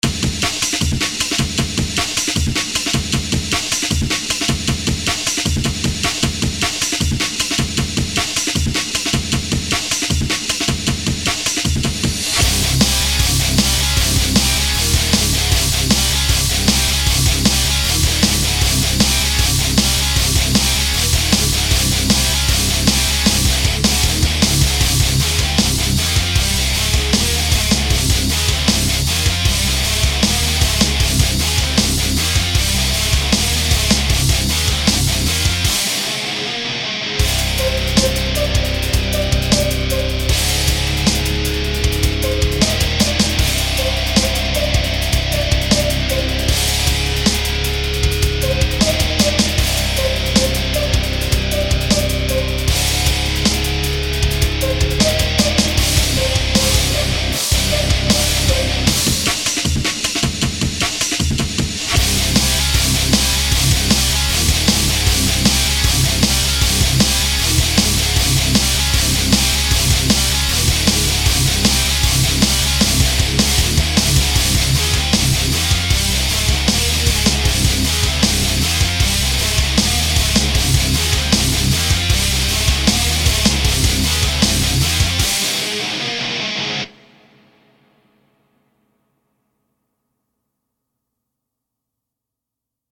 Industrial Metal Track - how to put some life into drums?
I noticed that the cymbals are a bit harsh, but I will work on it tommorow. My biggest problem is the kick.
Sound a bit plastic, and I don't know how to fix it.